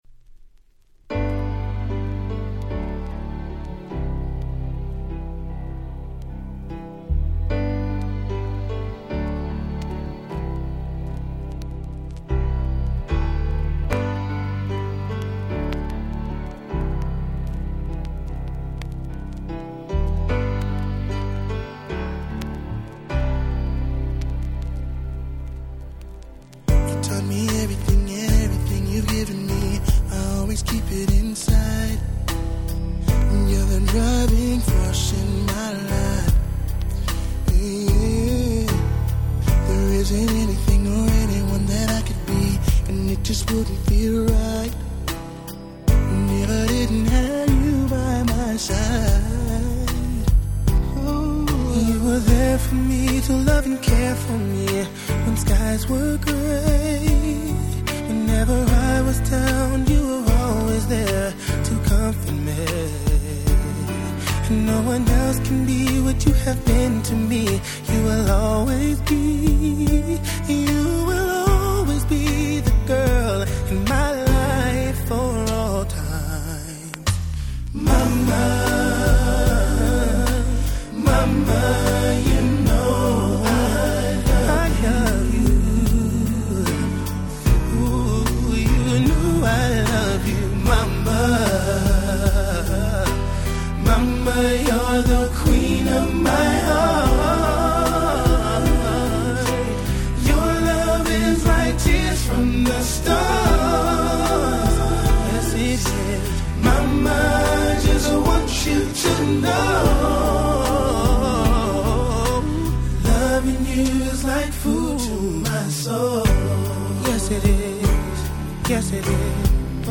97' Smash Hit Ballad !!
幸せ系Slow最高峰！
溜息が出る程に甘く、そして美しい最高のSlow Jam。